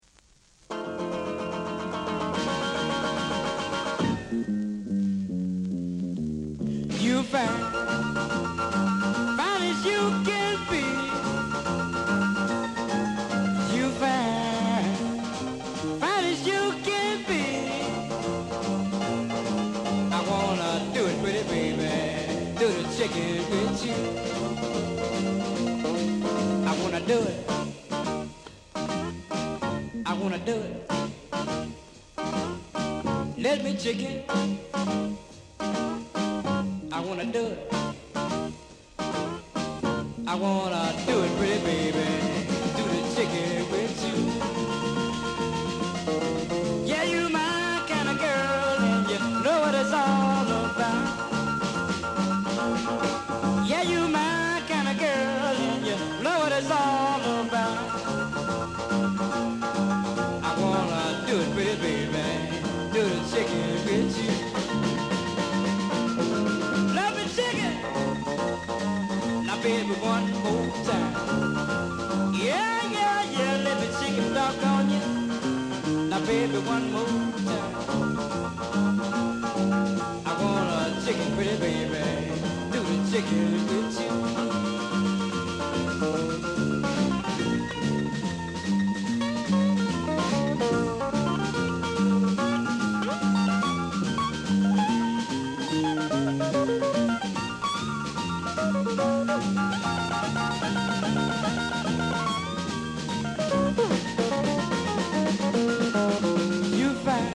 Blues Male Vocal